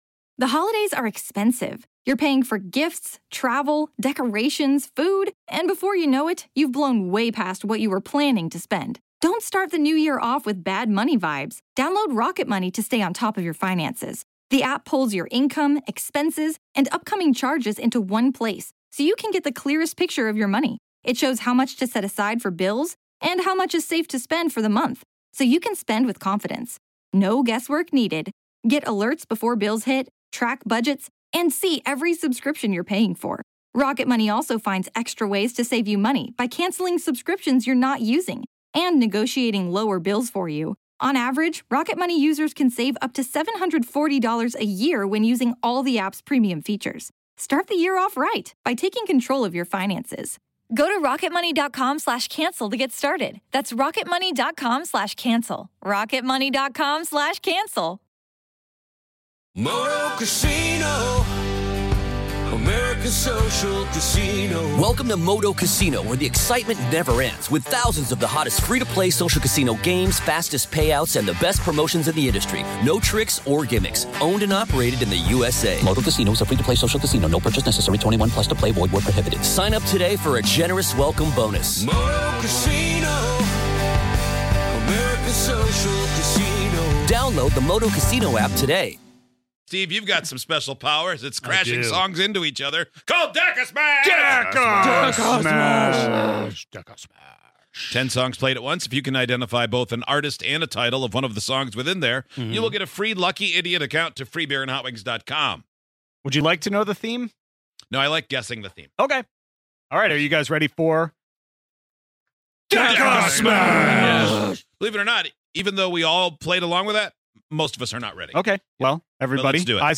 put together a list of ten travel-themed songs, smashed them all together, and played them at the same time. Can you guess any of the 10 songs from DECASMASH??